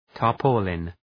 Προφορά
{tɑ:r’pɔ:lın} (Ουσιαστικό) ● αδιάβροχο